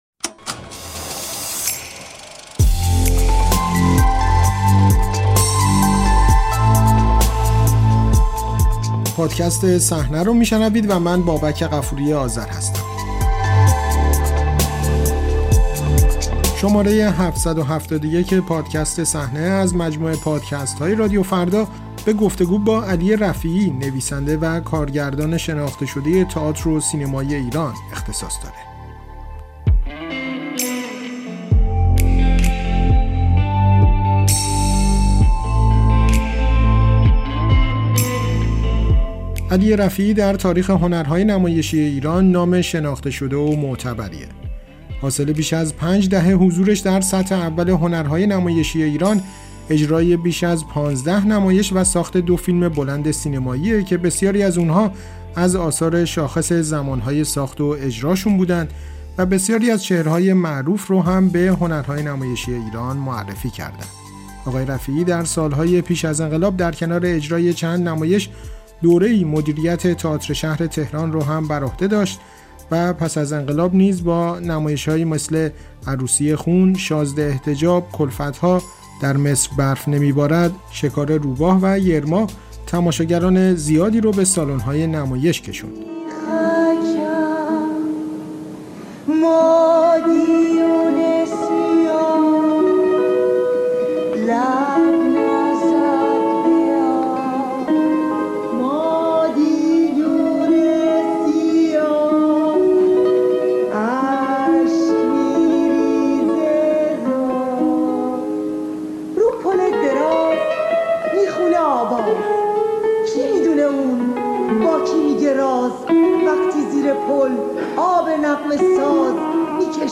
گفت‌وگو با علی رفیعی: در قبال جوان‌های ایران بی‌رحمی زیادی می‌شود